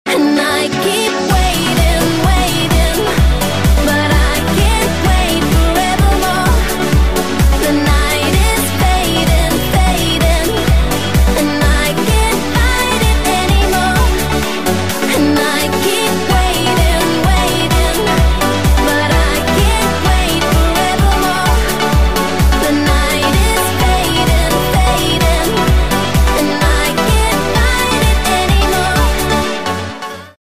• Качество: 128, Stereo
громкие
женский вокал
dance
Electronic
EDM
электронная музыка
progressive house
progressive trance